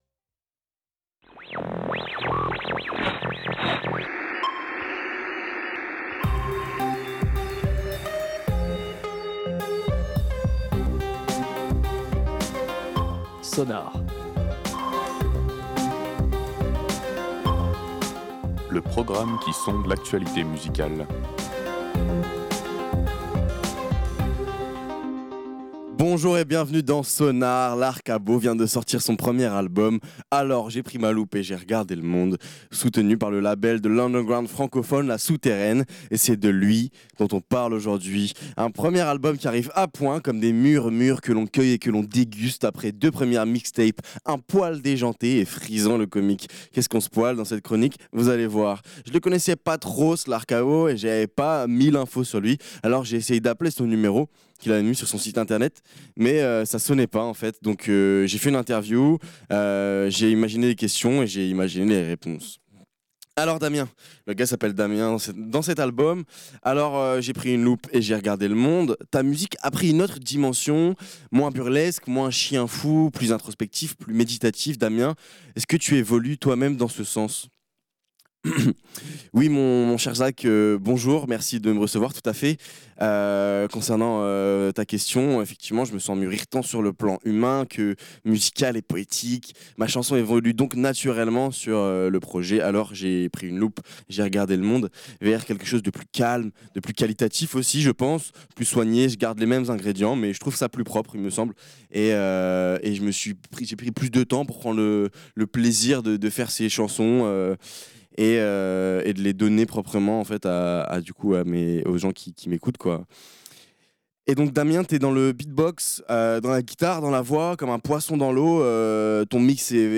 Un premier album qui arrive a point, comme des mûres mûres que l’on cueille et qu’on déguste, après deux premières mixtapes un poil déjantées et frisant le comique. Qu’est-ce qu’on se poile dans cette chronique, vous allez voir !
Et comme ça sonnait pas, bah j’ai fait une interview, les questions et j’ai imaginé les réponses.